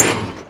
Minecraft Version Minecraft Version snapshot Latest Release | Latest Snapshot snapshot / assets / minecraft / sounds / mob / blaze / hit4.ogg Compare With Compare With Latest Release | Latest Snapshot